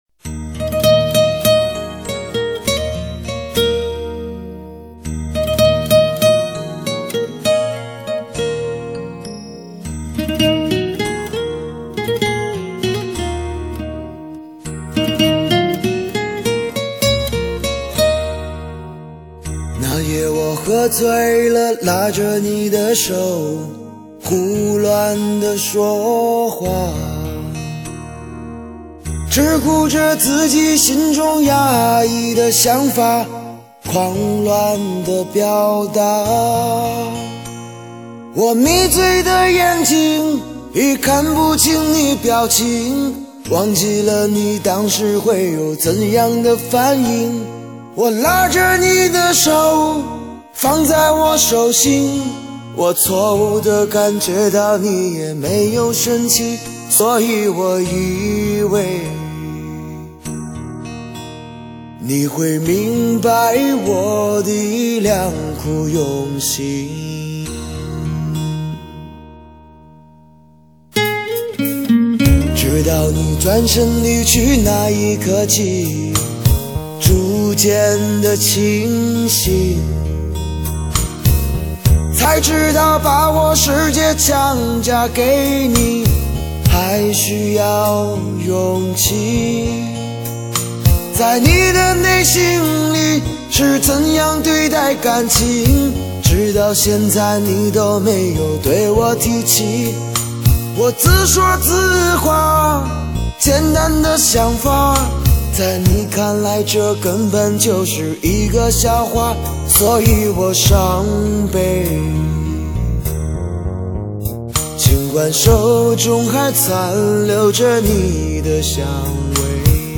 流行 收藏 下载